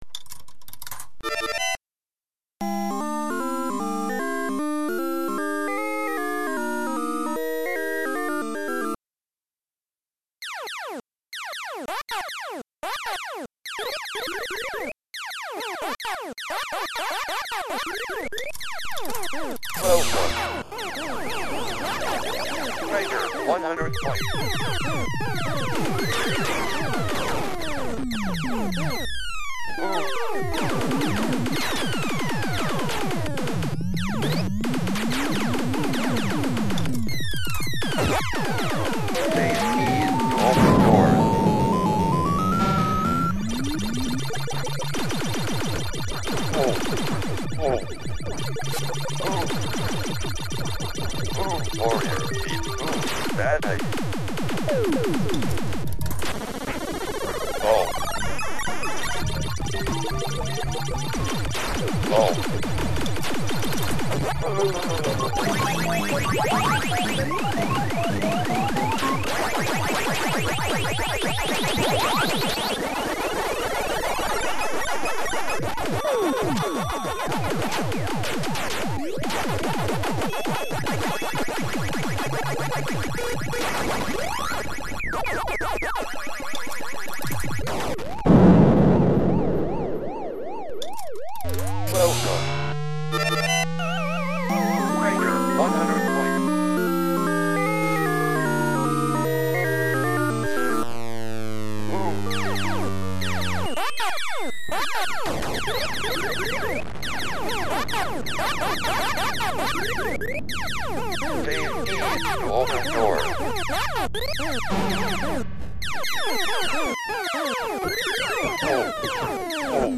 Sounds from an arcade - new file
Second, I'm trying to get my own "arcade ambiance" soundtrack going.
There are things I'd change...the Gauntlet sounds, for example, are stereo.  They need to be mono because the sounds are coming from both sides.
small_arcade.mp3